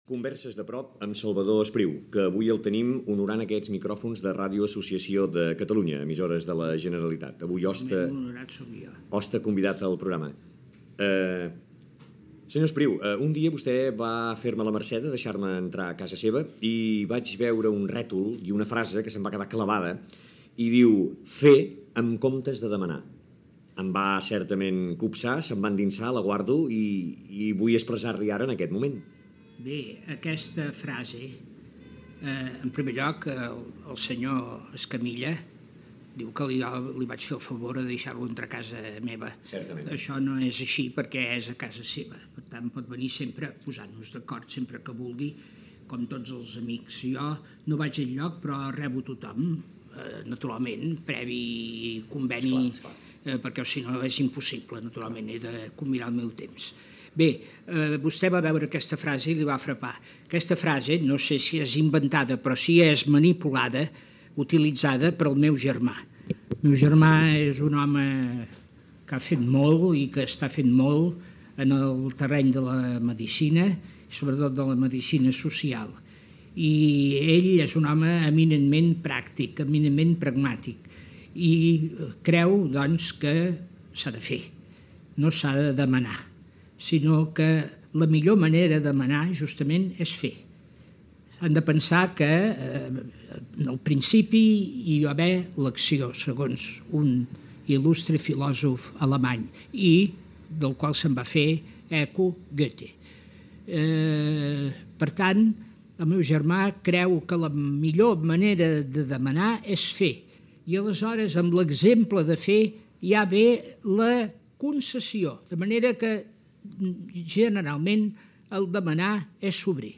Identificació del programa i de l'emissora, entrevista a l'escriptor Salvador Espriu: una frase sobre el fet del demanar del seu germà metge, l'estima per Catalunya, els indrets de parla catalana, l'aprenentatge del català